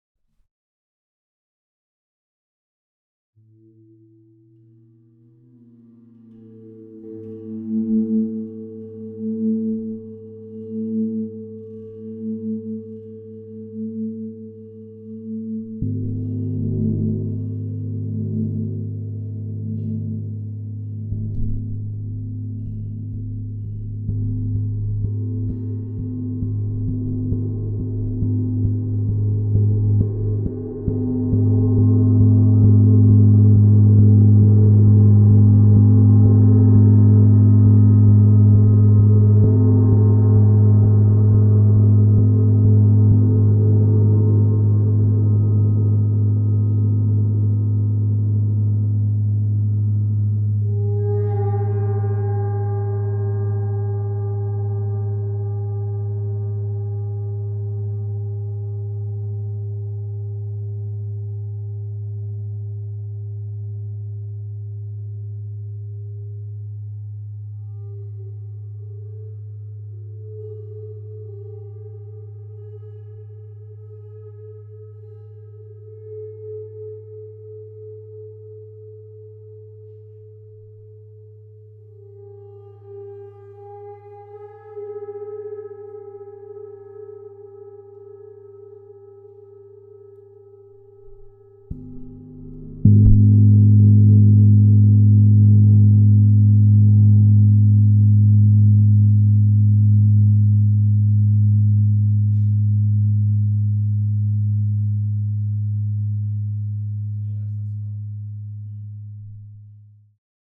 Le chant du gong